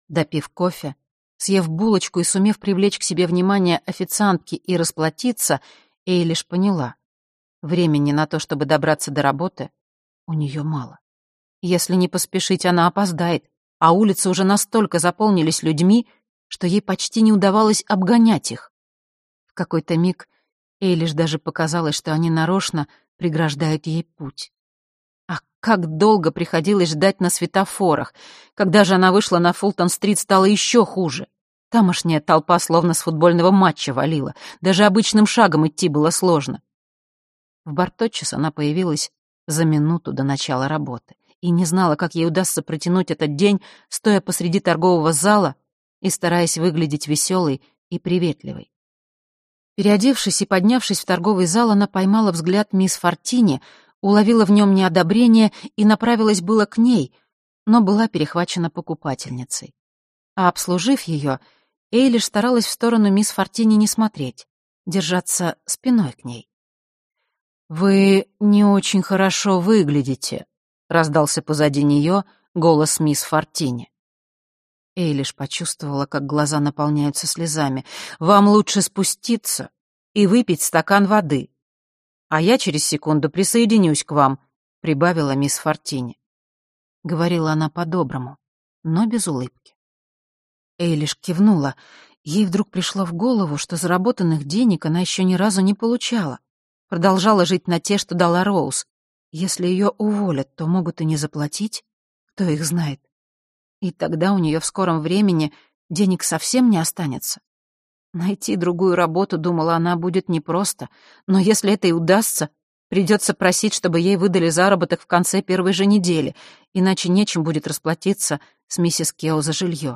Аудиокнига Бруклин | Библиотека аудиокниг